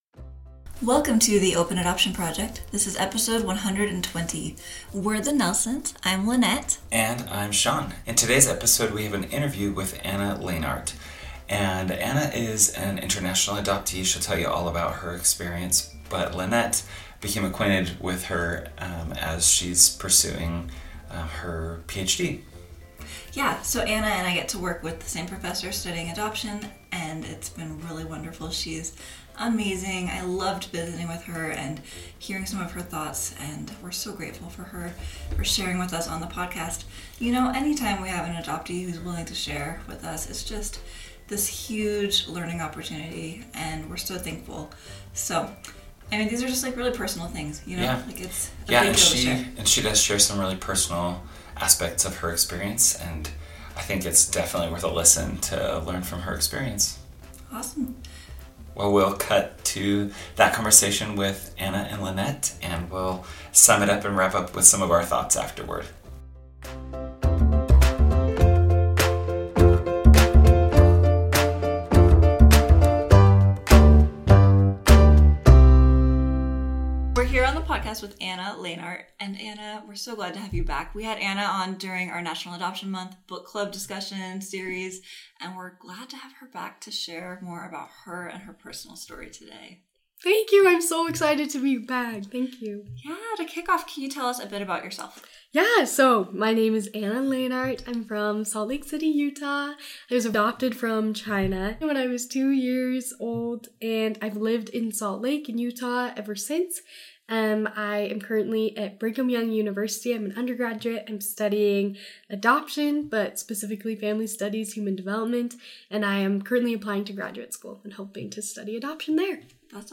From navigating identity to facing the complexities of belonging, this conversation sheds light on the realities of international adoption.